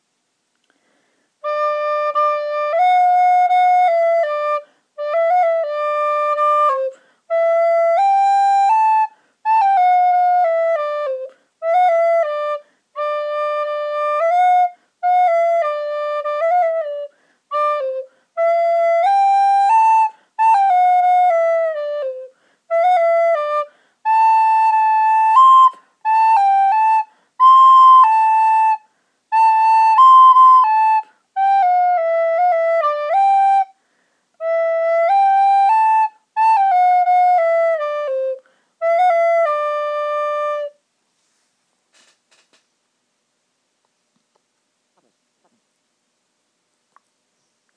Me playing the folk song Palestinalied on the recorder